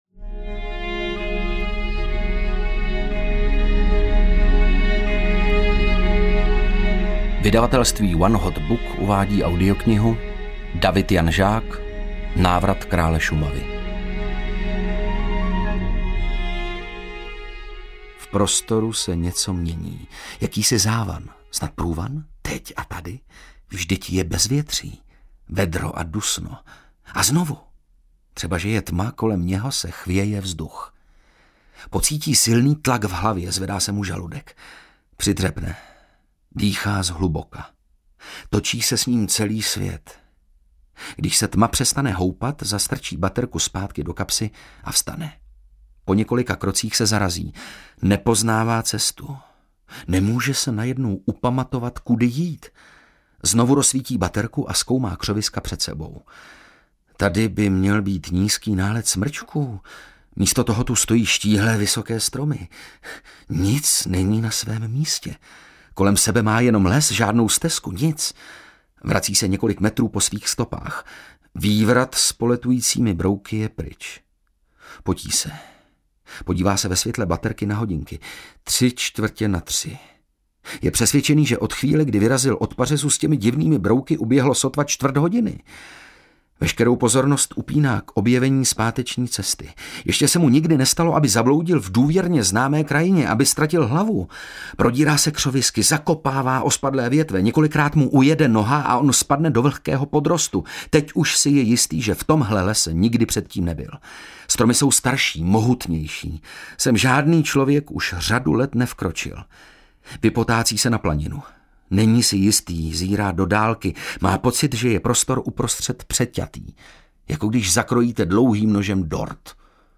Návrat Krále Šumavy audiokniha
Ukázka z knihy